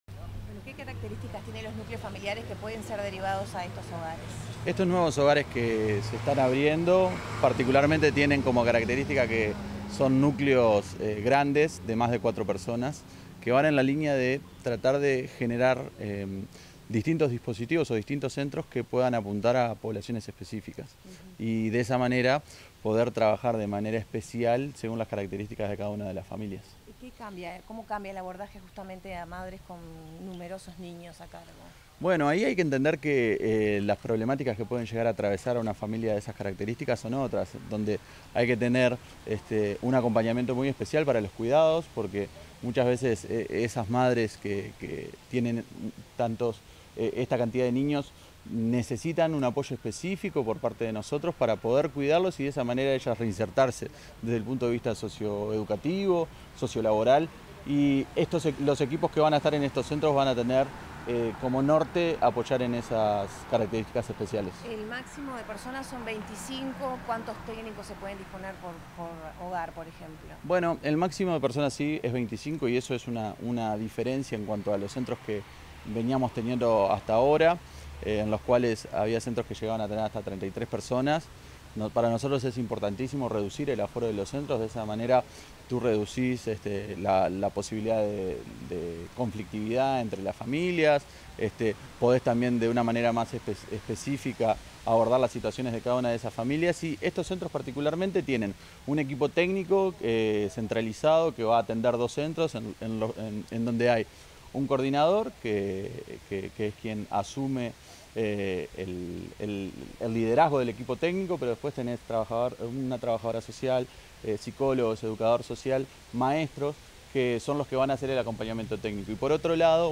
Declaraciones del director de Programas para Personas en Situación de Calle del Mides, Gabriel Cunha
Declaraciones del director de Programas para Personas en Situación de Calle del Mides, Gabriel Cunha 16/02/2023 Compartir Facebook X Copiar enlace WhatsApp LinkedIn Tras la inauguración de un centro de 24 horas del Ministerio de Desarrollo Social (Mides), el 16 de febrero, el director de la Coordinación de Programas para Personas en Situación de Calle, Gabriel Cunha, realizó declaraciones a la prensa.